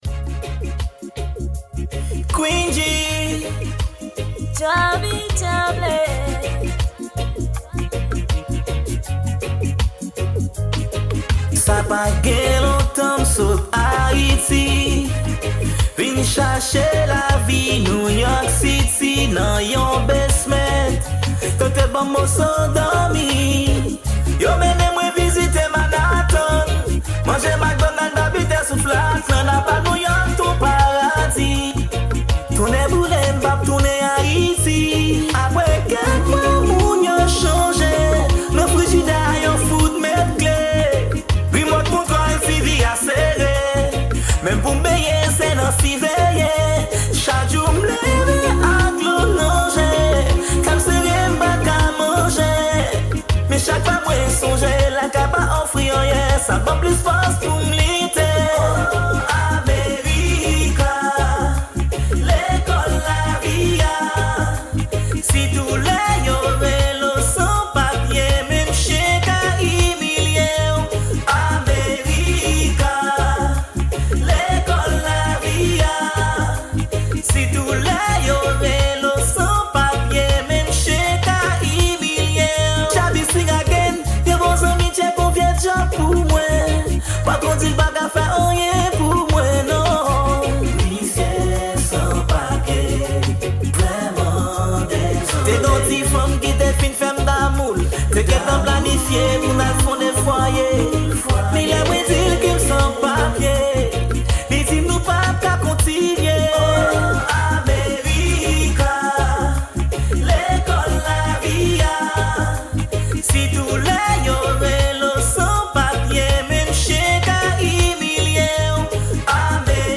Genre: RAO.